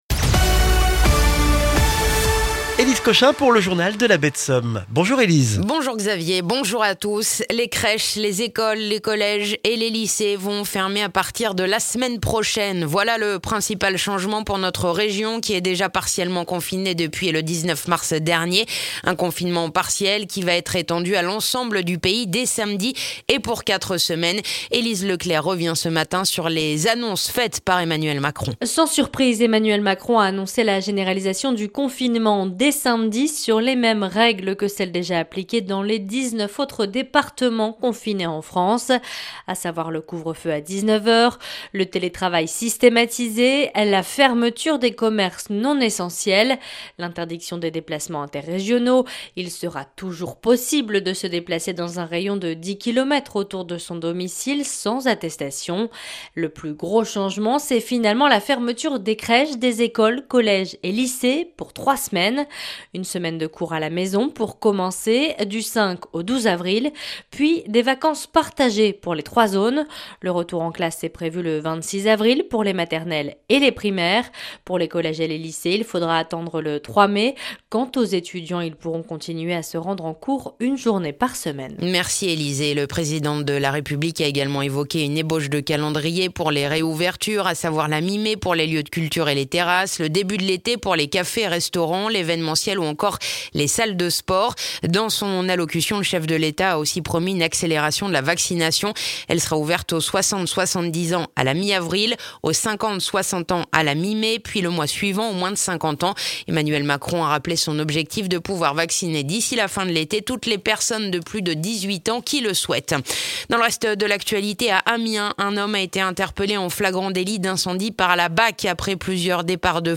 Le journal du jeudi 1er avril en Baie de Somme et dans la région d'Abbeville